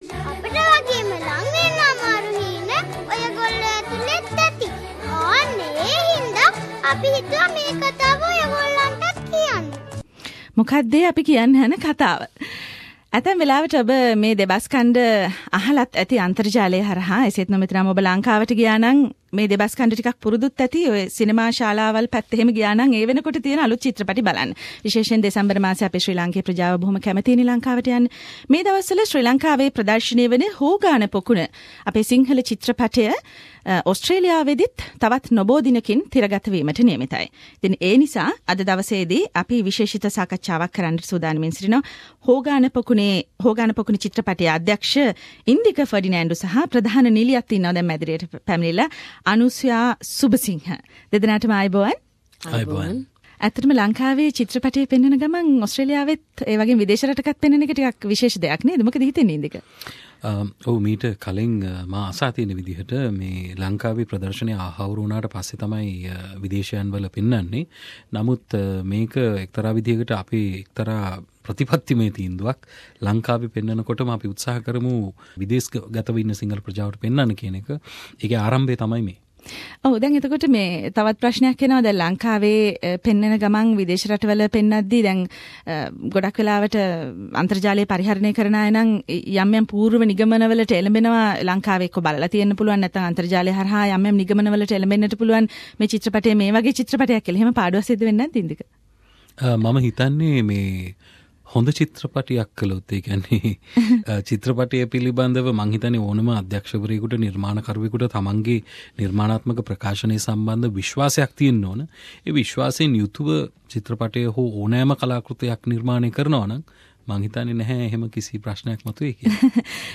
Source: : SBS studio